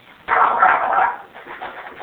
a small breed dog. :)
DOG.wav